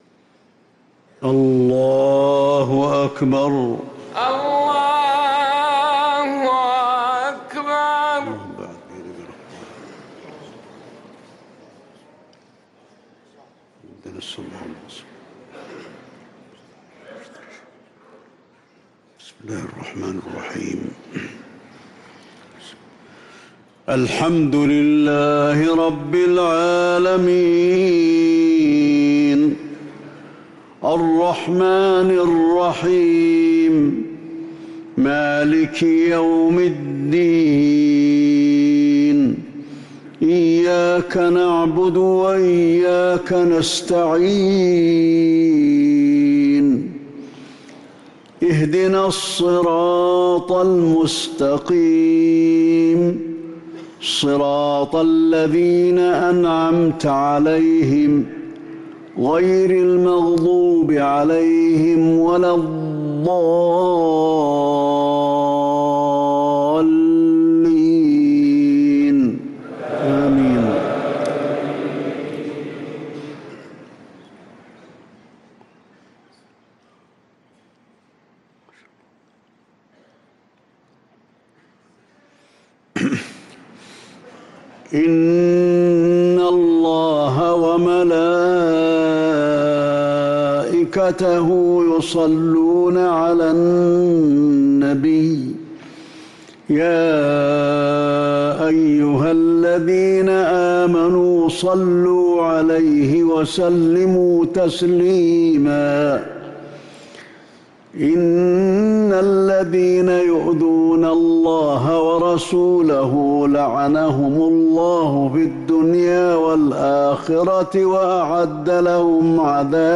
صلاة المغرب للقارئ علي الحذيفي 23 جمادي الأول 1445 هـ
تِلَاوَات الْحَرَمَيْن .